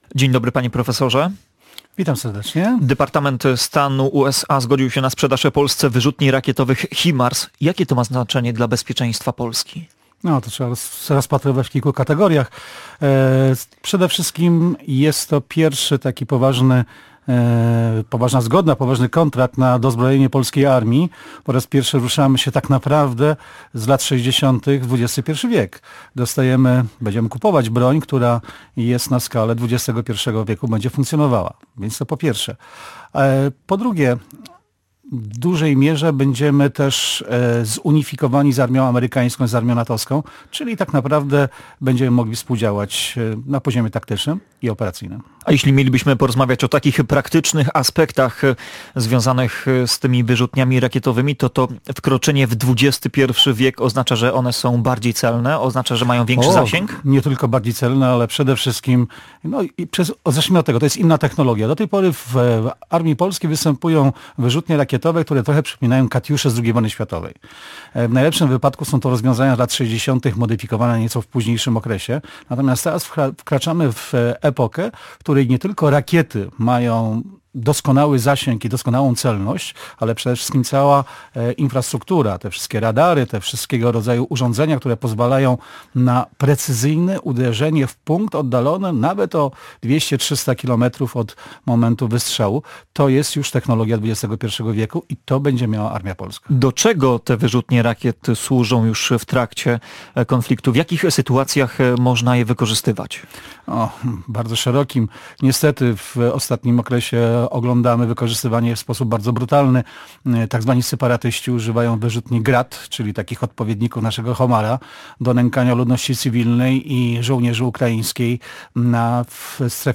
Jakie znaczenie ich zakup będzie miał dla bezpieczeństwa Polski? Na to pytanie odpowiadał Gość dnia Radia Gdańsk.